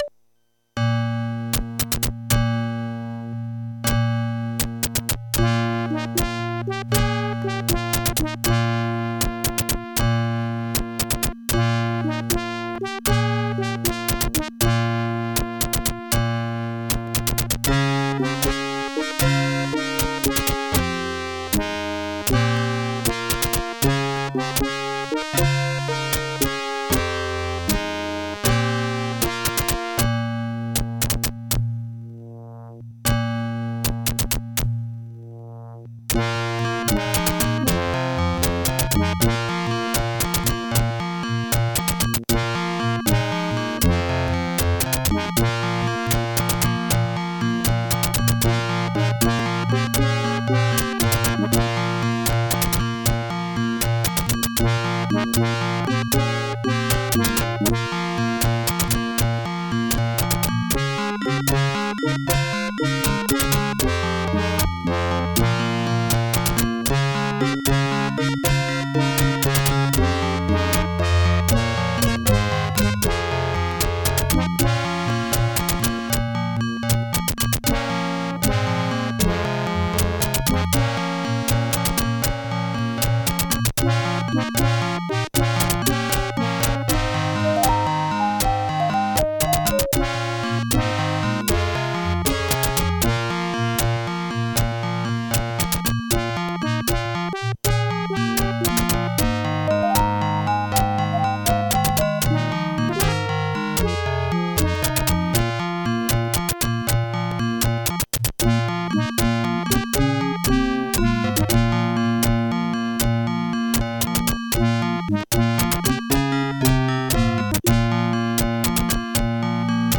Creative SoundBlaster 16 ct2740
* Some records contain clicks.